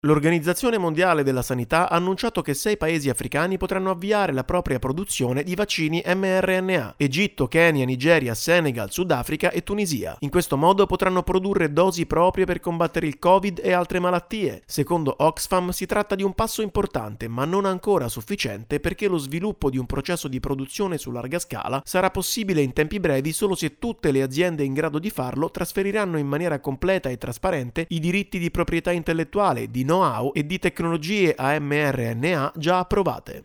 Sei paesi africani sono stati scelti per avviare la propria produzione di vaccini mRNA. Ma per sconfiggere il Covid c’è bisogno della liberalizzazione dei brevetti. Il servizio